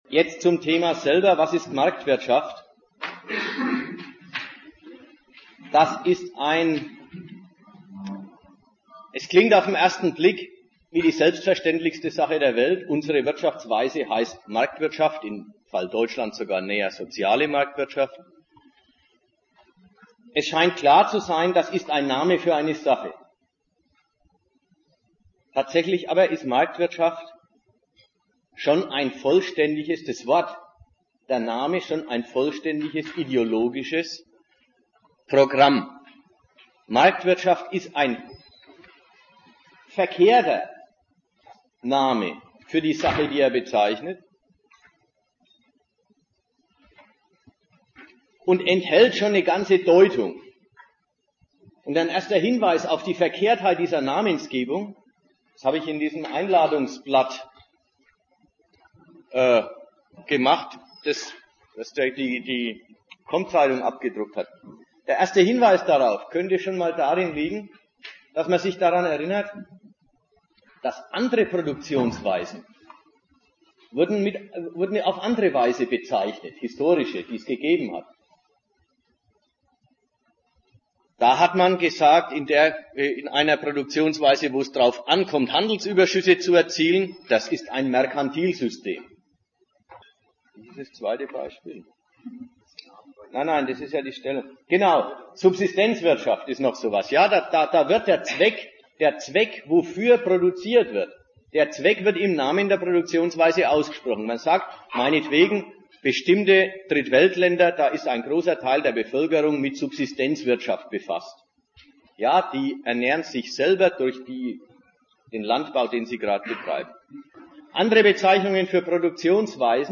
Datum 22.01.2001 Ort Nürnberg Themenbereich Arbeit, Kapital und Sozialstaat Wissenschaft und Ausbildung Veranstalter Sozialistische Gruppe Dozent Gastreferenten der Zeitschrift GegenStandpunkt Die Aufnahme ist entsprechend der Gliederung aufgeteilt.